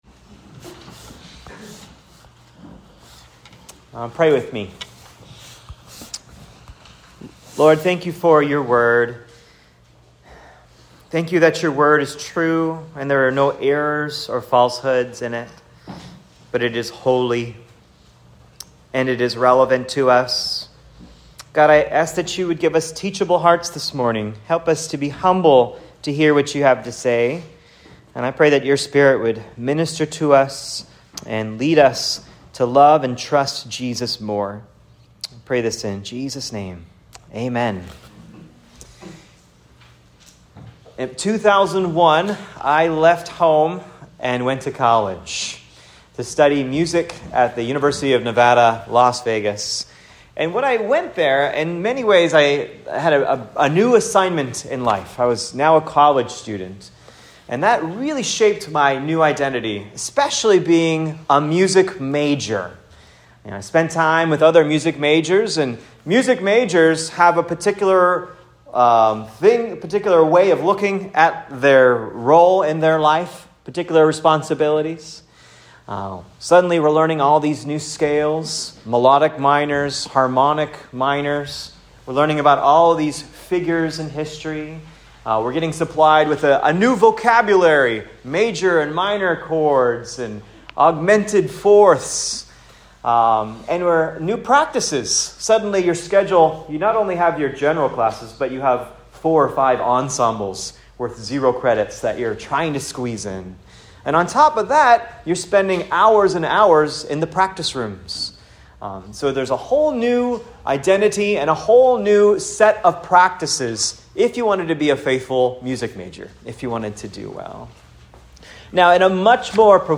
“Live It Out!” Ephesians 4:25-5:2 SERMON